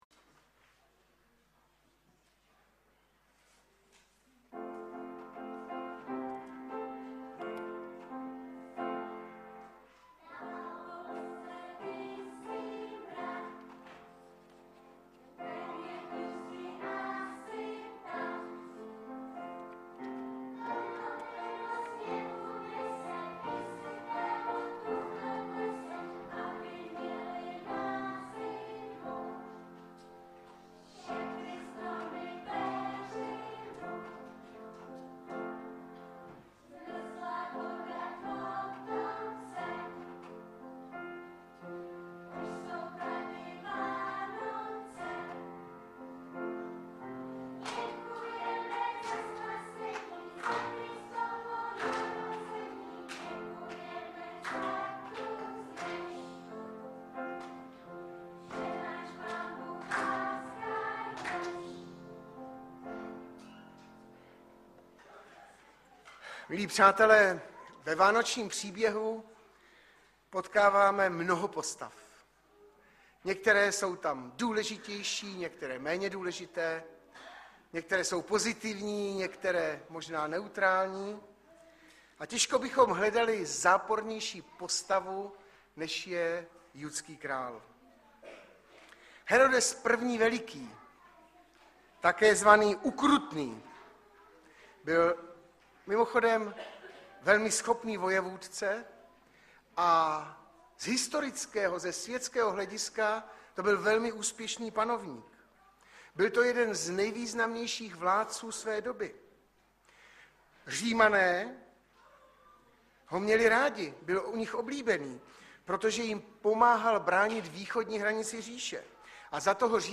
23.12.2018 - DĚTSKÁ VÁNOČNÍ SLAVNOST